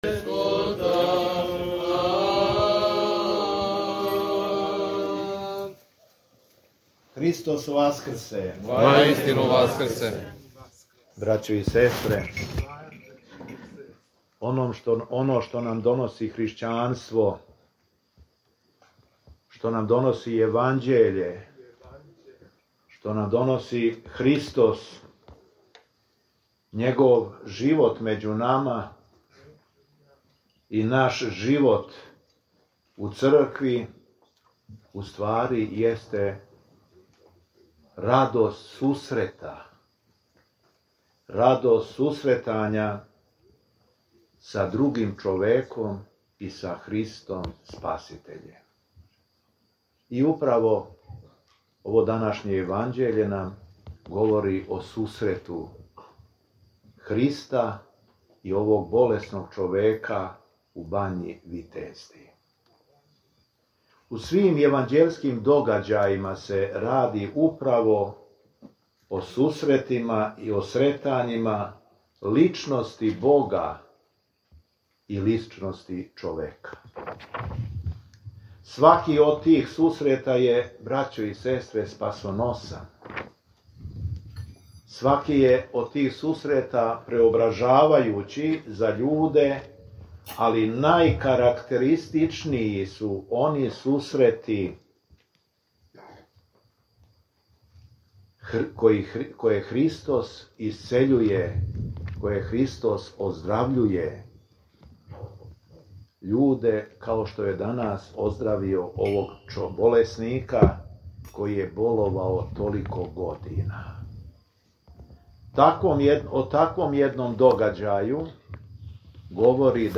Беседа Његовог Високопреосвештенства Митрополита шумадијског г. Јована
Након прочитаног зачала из Светог Јеванђеља, Архиепископ се обратио верном народу богонадахнутом беседом: